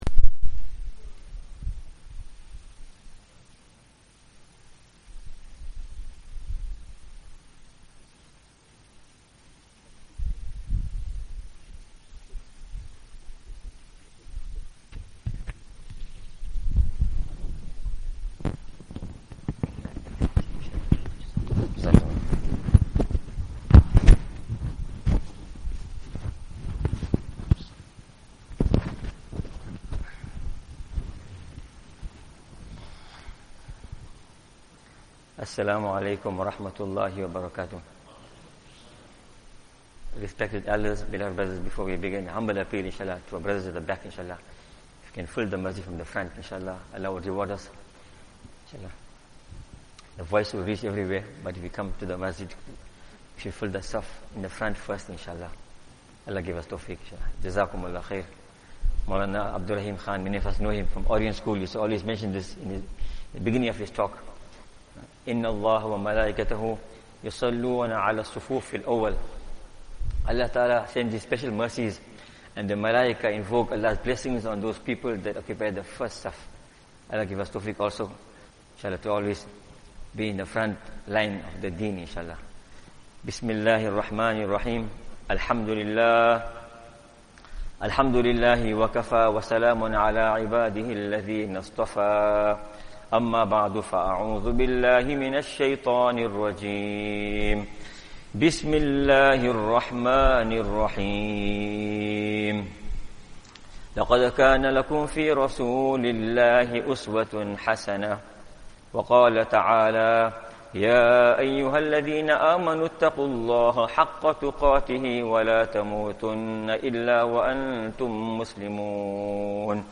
Easily listen to Islamic Lecture Collection